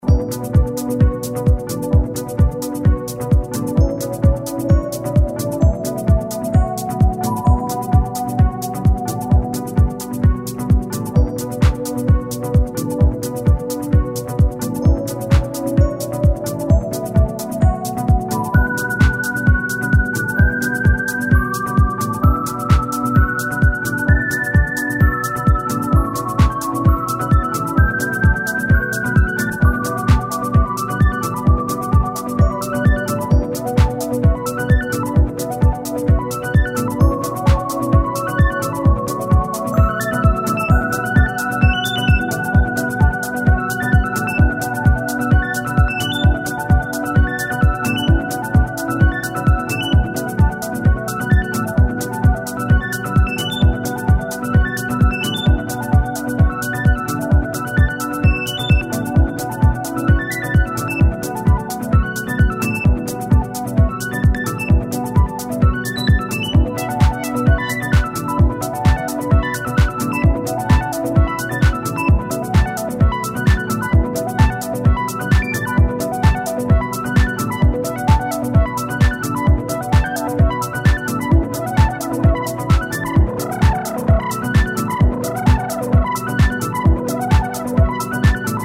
electronic
emotive, hi-tech soul